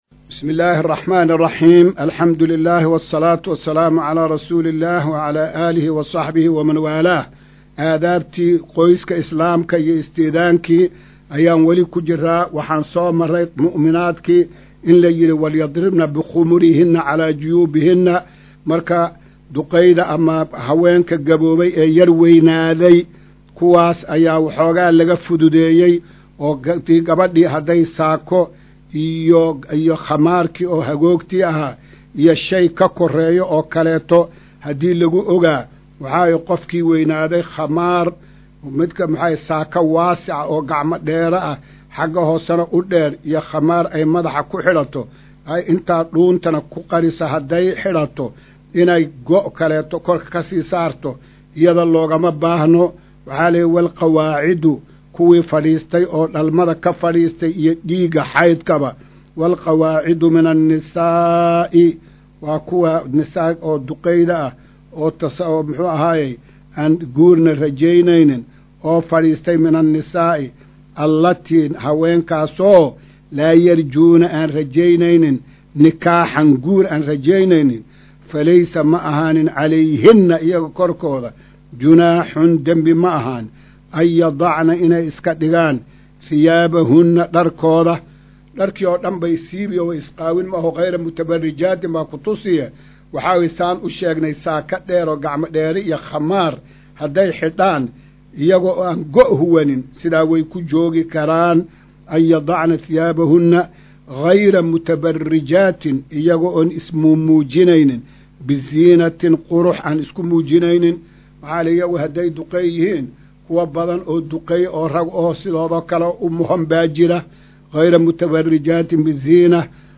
Maqal:- Casharka Tafsiirka Qur’aanka Idaacadda Himilo “Darsiga 173aad”